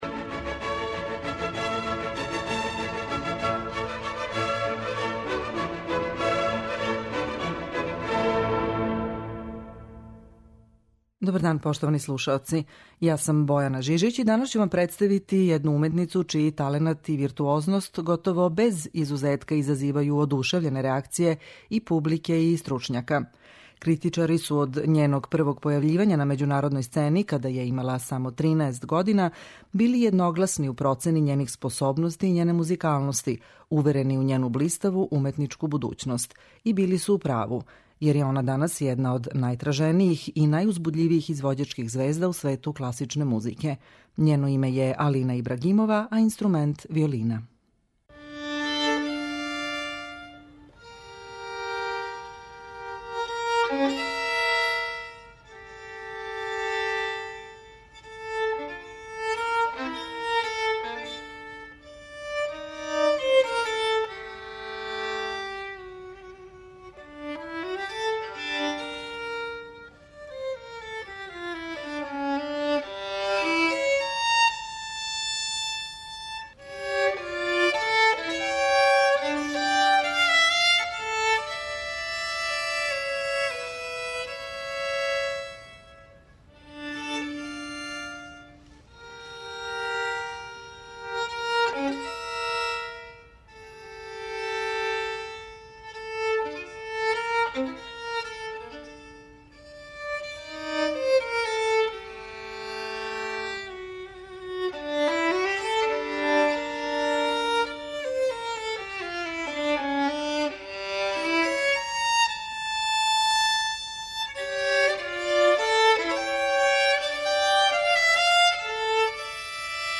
виолинисткиње